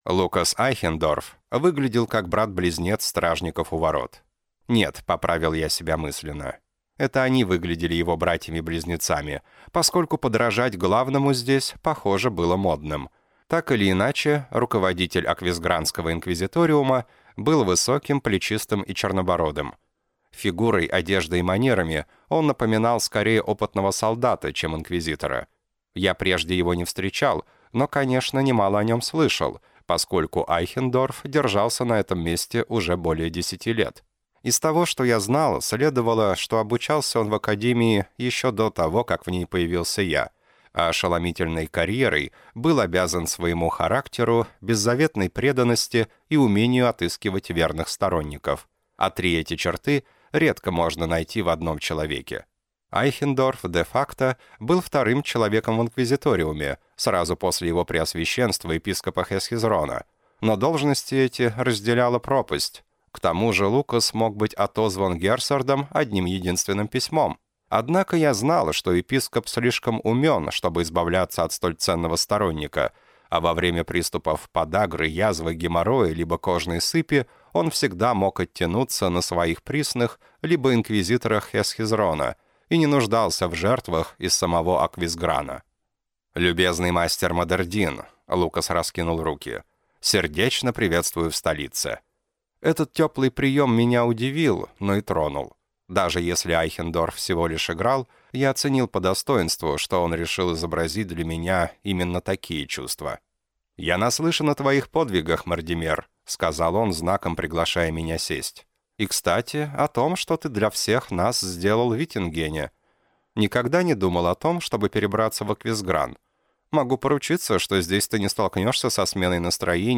Аудиокнига Ловцы душ | Библиотека аудиокниг